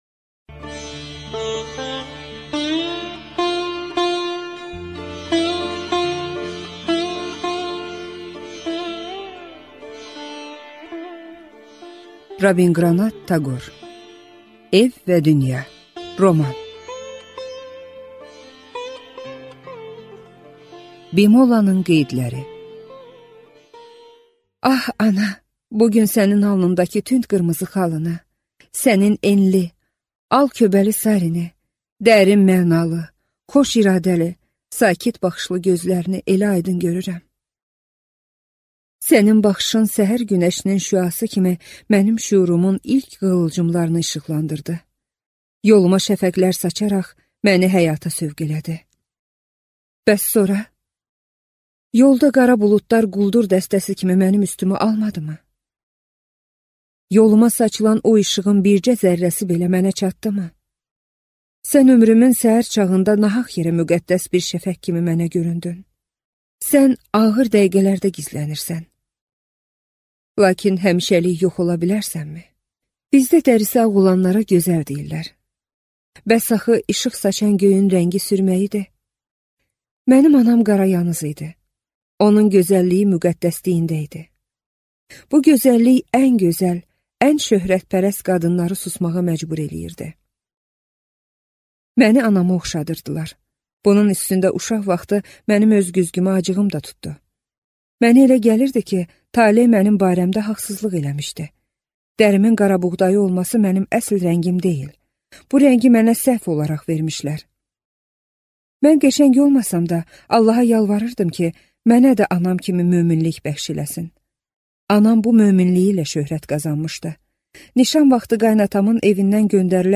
Аудиокнига Ev və dünya | Библиотека аудиокниг